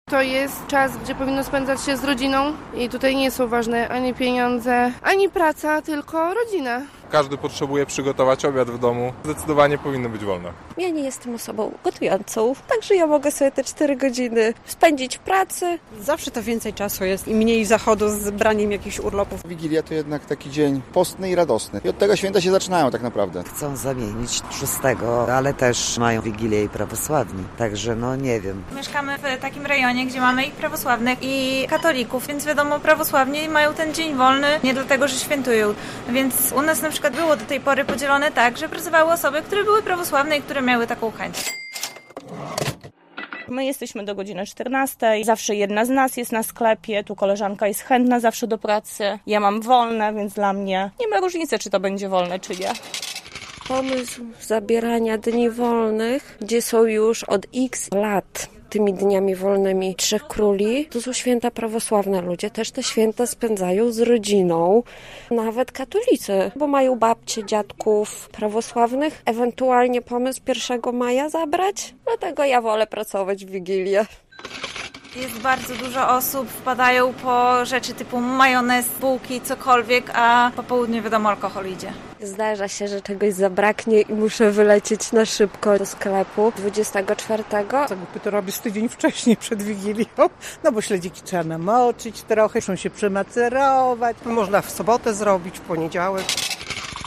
Słuchaj: Co na temat Wigilii wolnej od pracy sądzą białostoczanie? - relacja